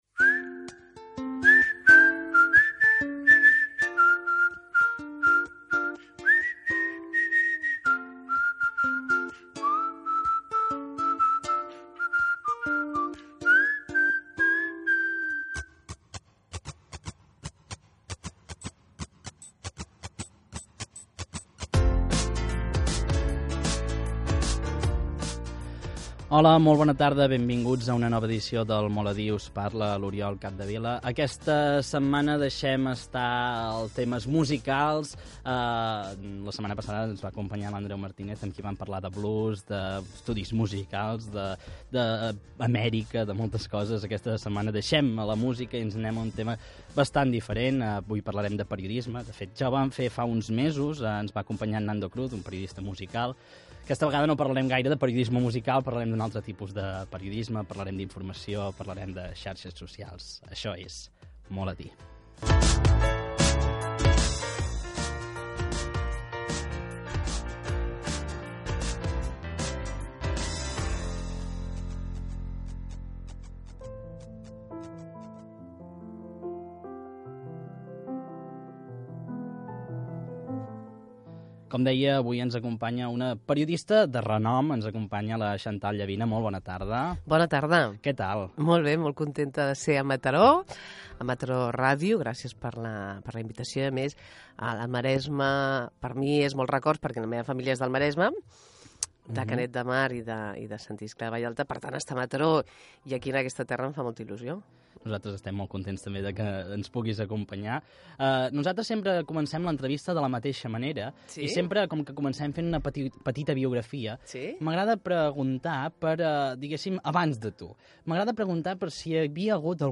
Nom programa Molt a dir Gènere radiofònic Entreteniment